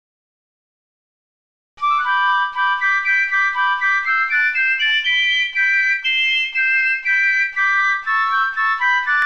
Basler Märsch
(numme d Aafäng)